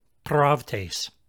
Hear It> Incorrect pronunciation without diaeresis.